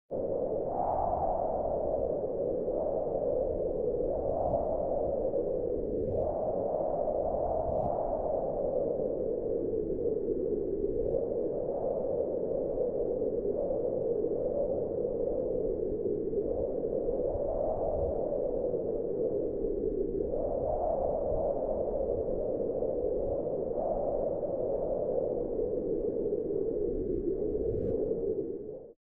wind_howl.ogg